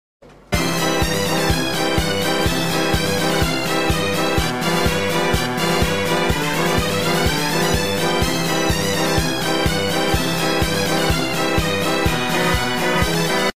Download Circus sound effect for free.
Circus